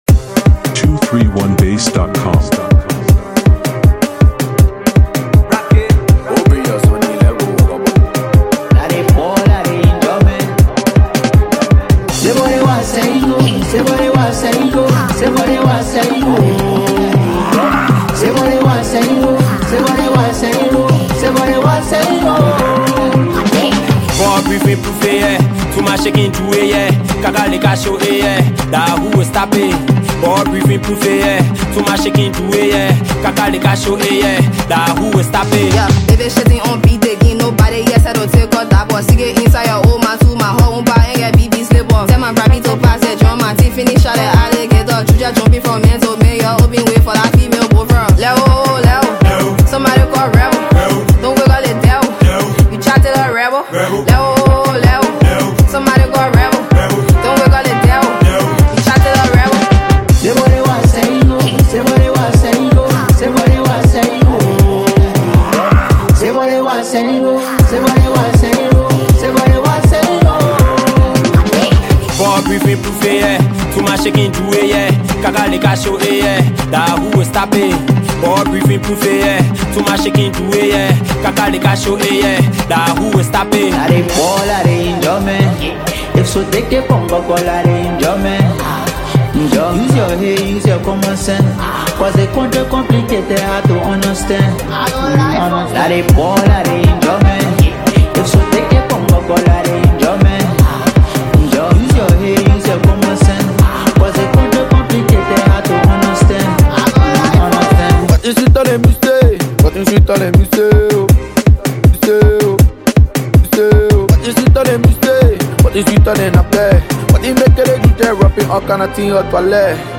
hype jam
Hip-co/Rap